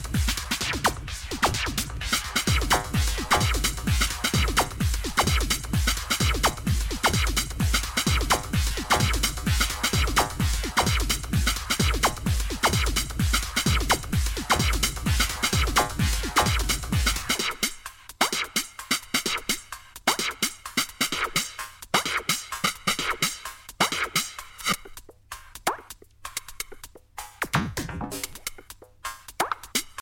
a música aqui é alienígena e minimalista